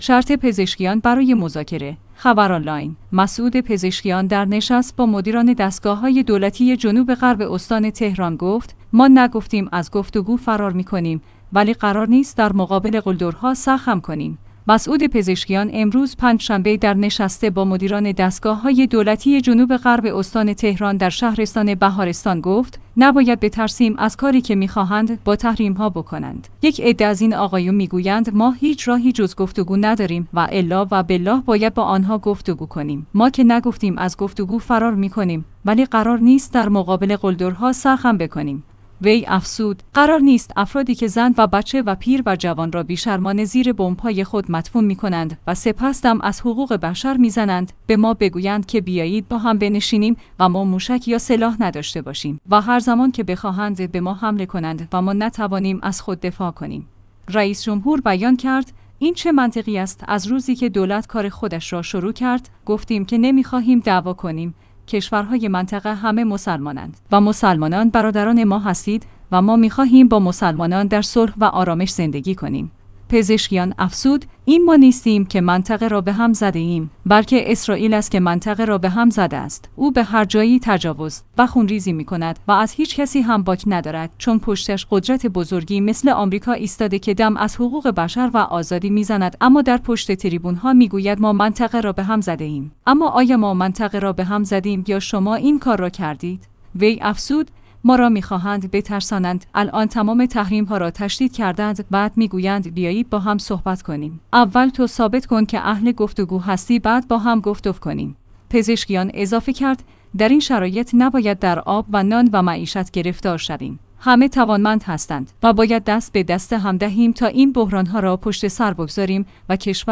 خبرآنلاین/ مسعود پزشکیان در نشست با مدیران دستگاه‌های دولتی جنوب غرب استان تهران گفت: ما نگفتیم از گفت‌وگو فرار می‌کنیم ولی قرار نیست در مقابل قلدرها سر خم کنیم.